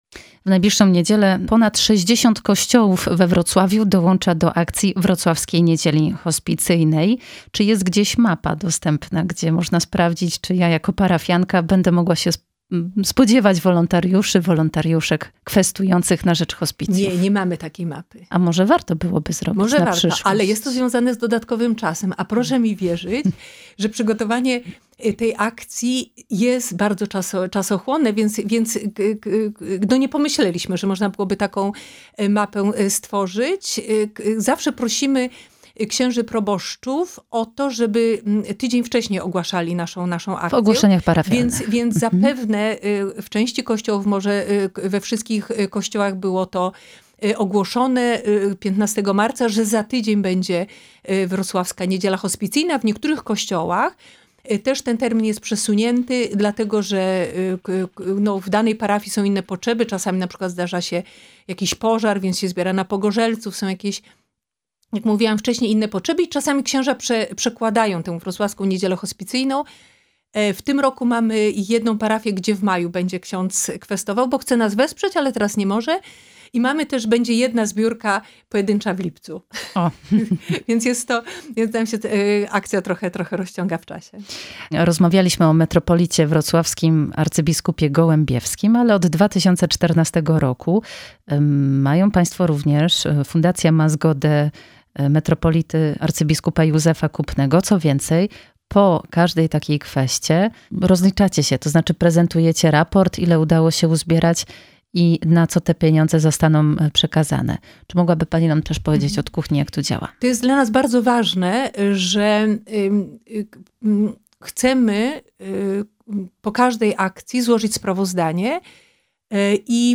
Gościem Radia Rodzina jest